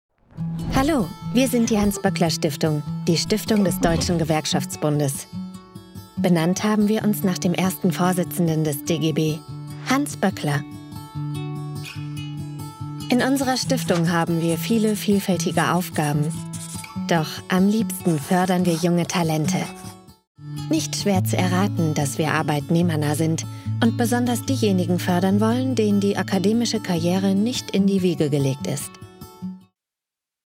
Joven, Accesible, Versátil, Cálida, Suave
Corporativo